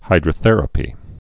(hīdrə-thĕrə-pē)